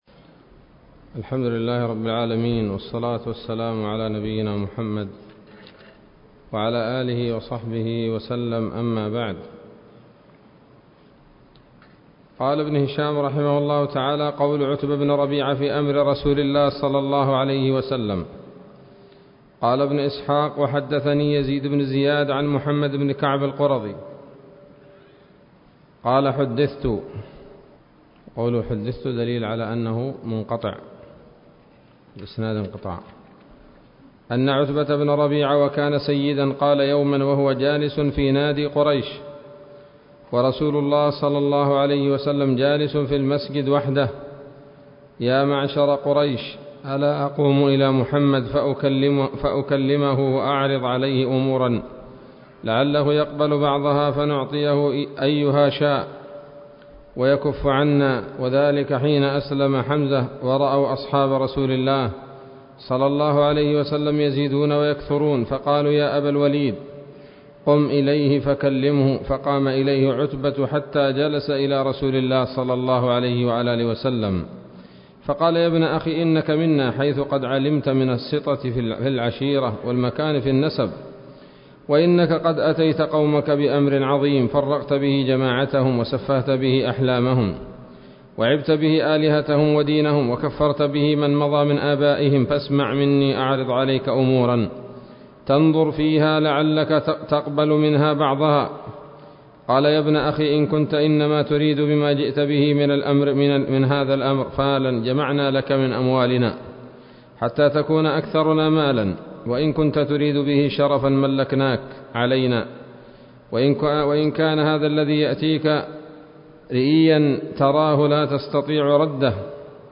الدرس الحادي والثلاثون من التعليق على كتاب السيرة النبوية لابن هشام